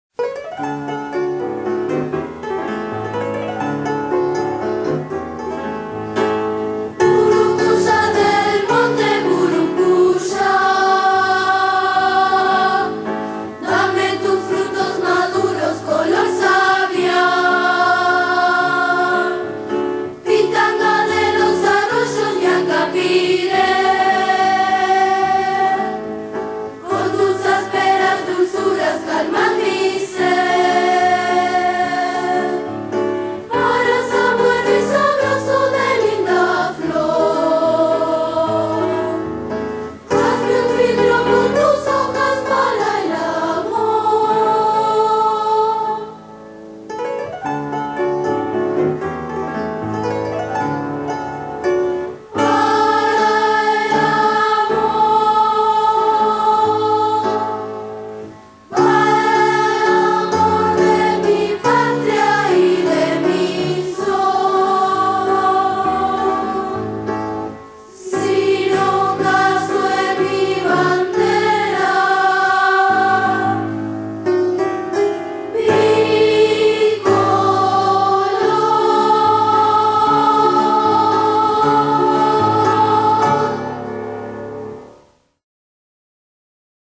Coro de la Escuela Nacional de Música. Frutos Nativos